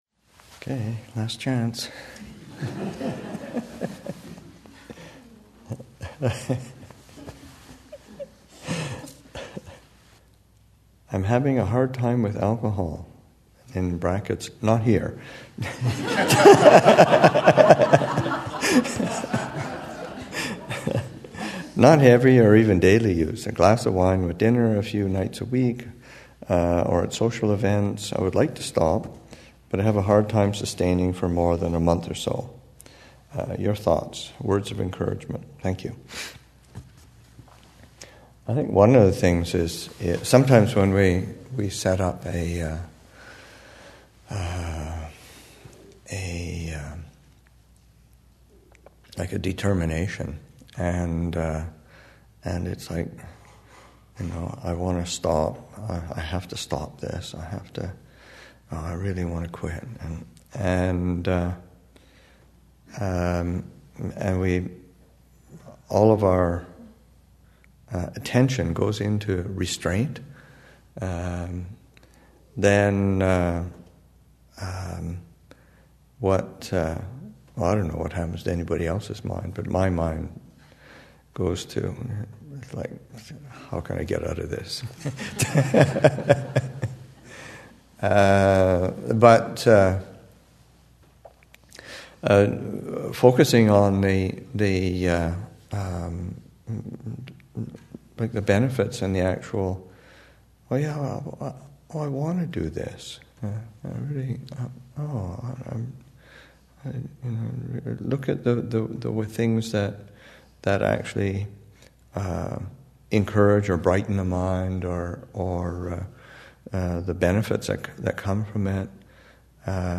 Audio from the 2015 Abhayagiri Thanksgiving Monastic Retreat held at the Angela Center in Santa Rosa, California November 20 through November 29, 2015.